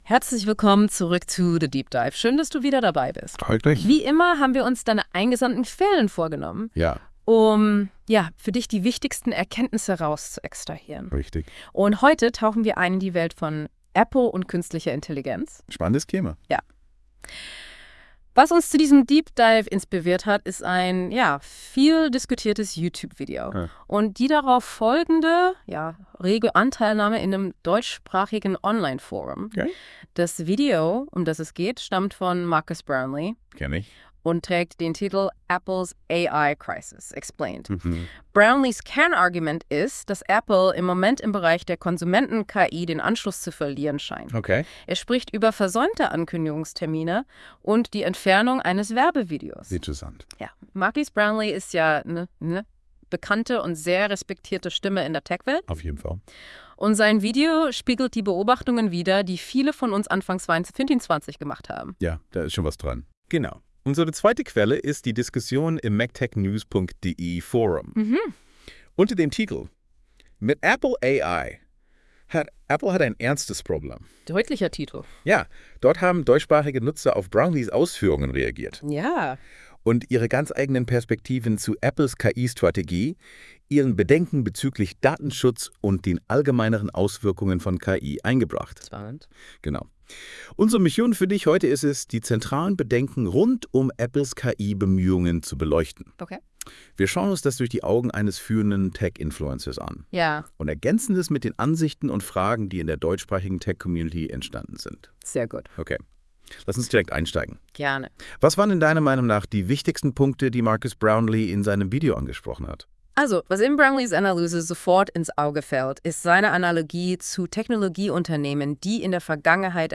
Diese von Google erzeugten Radio Talk Shows sind schon beeindruckend.
Ich habe diesen Thread soeben Gemini zu Diskussion überstellt: Die Erstellung dieser 18 Minuten Audio-Datei hat ~2 Minuten gedauert.